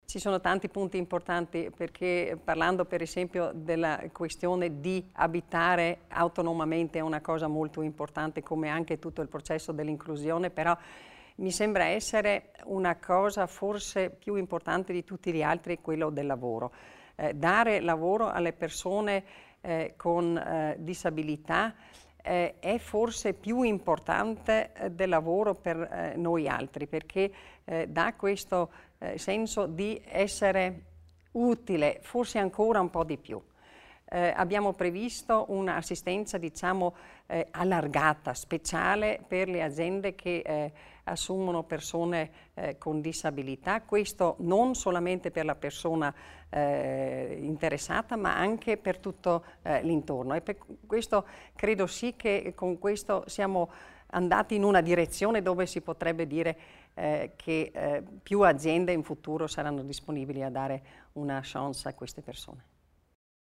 L'Assessore Stocker presenta il disegno di legge a tutela dei disabili